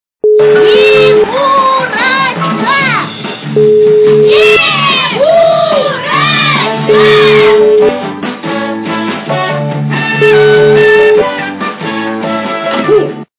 качество понижено и присутствуют гудки.